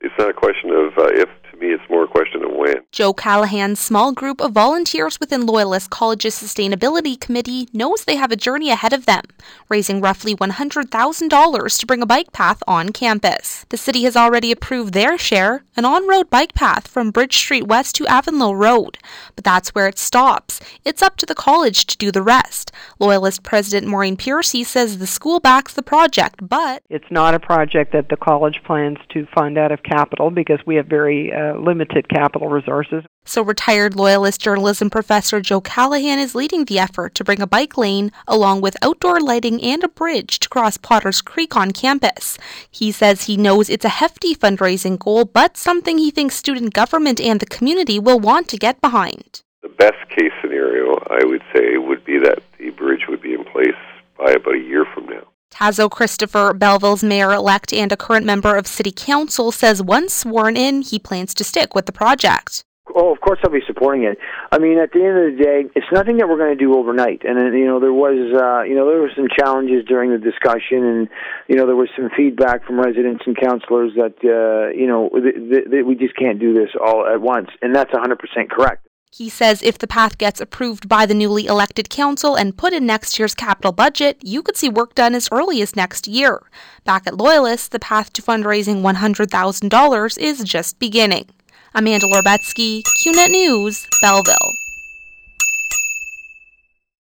Check out the full 91X report below: